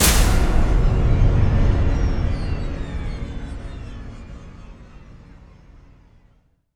LC IMP SLAM 8C.WAV